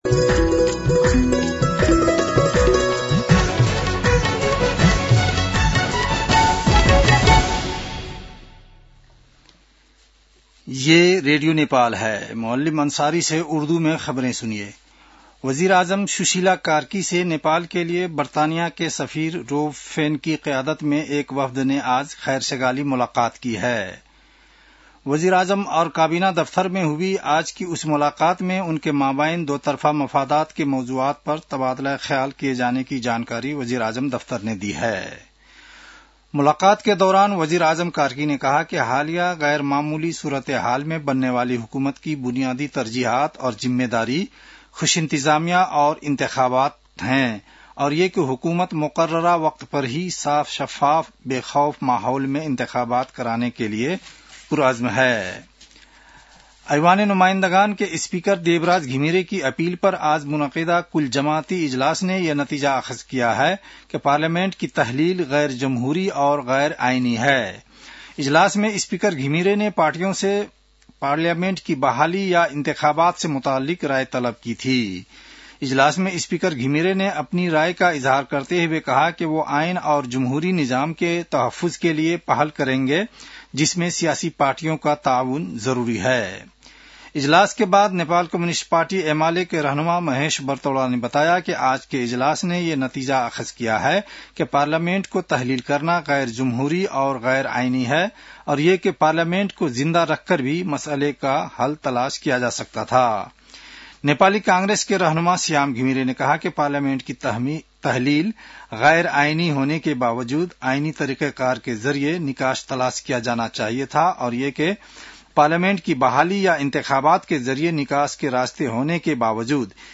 उर्दु भाषामा समाचार : २९ असोज , २०८२